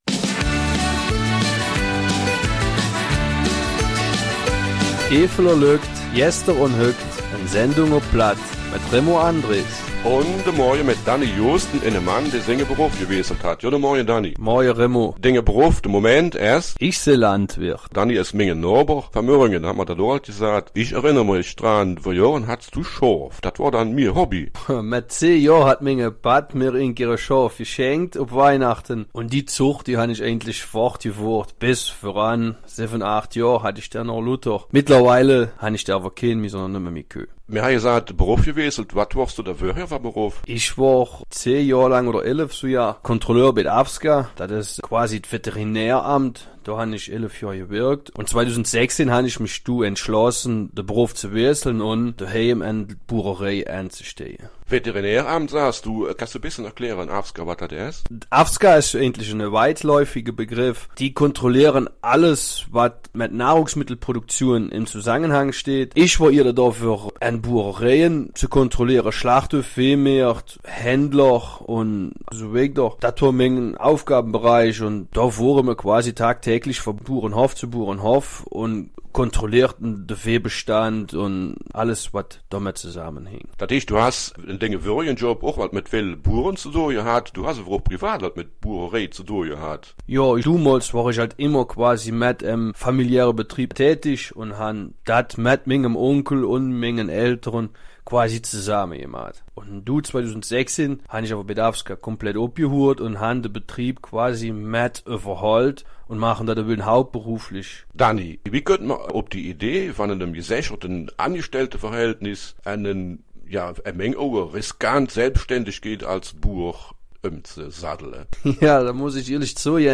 Eifeler Mundart: Vom Angestellten zum Biolandwirten